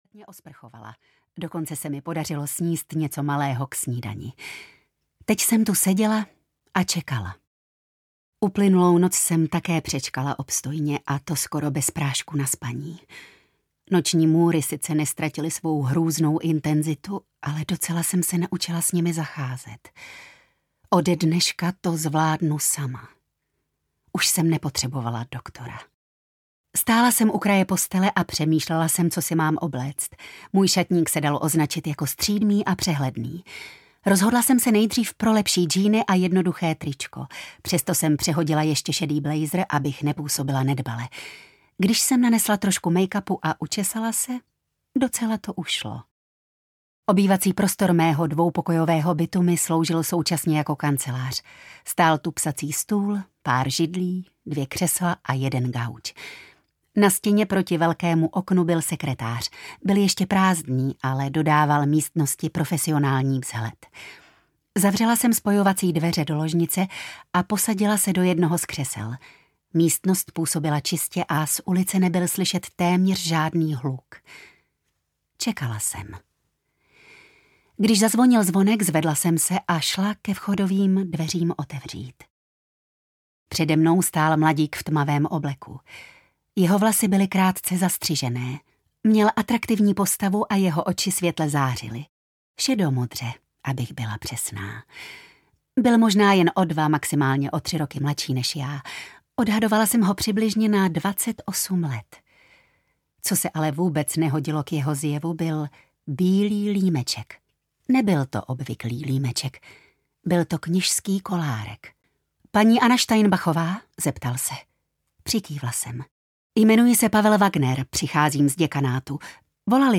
Ráj mrtvých dětí audiokniha
Ukázka z knihy